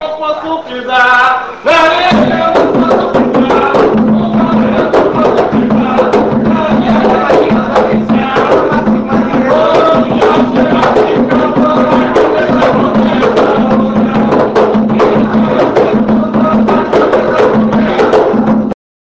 Todos os pontos aqui apresentados foram gravados ao vivo.